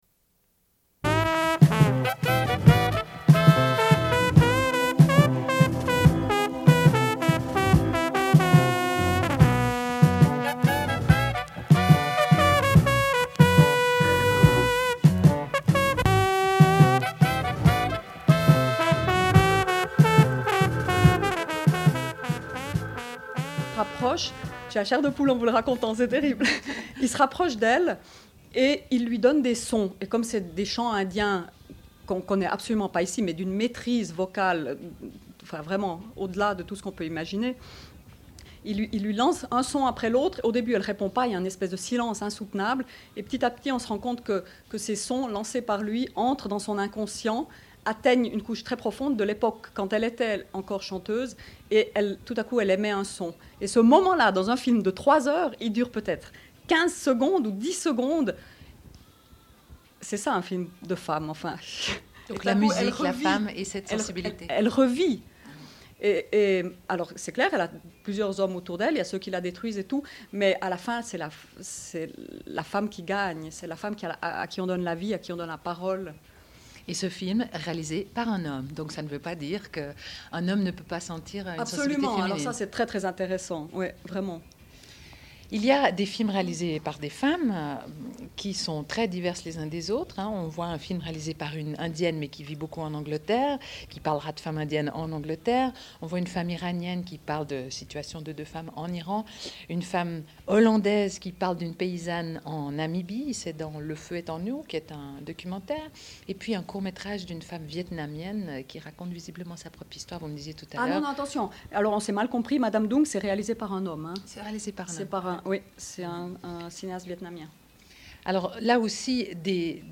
Une cassette audio, face B29:32
Sommaire de l'émission : « spécial Fribourg », diffusion d'entretiens sur le Festival de films de Fribourg.